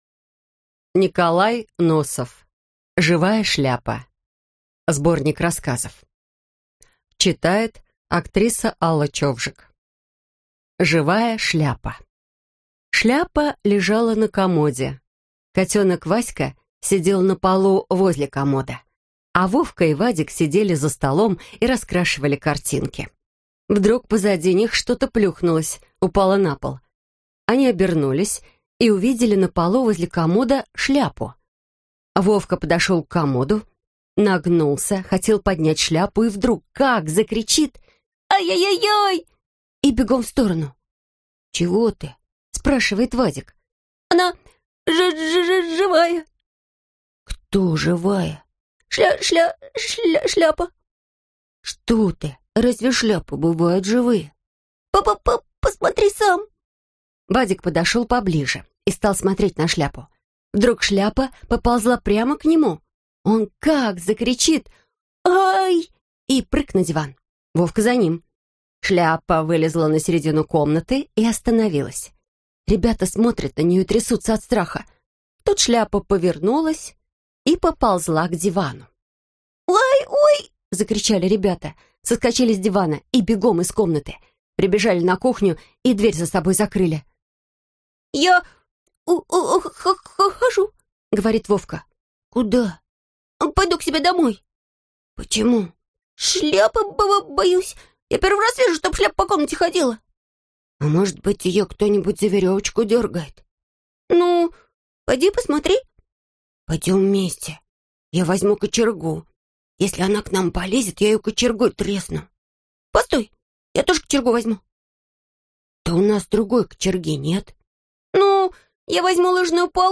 Аудиокнига Живая шляпа (сборник) | Библиотека аудиокниг
Прослушать и бесплатно скачать фрагмент аудиокниги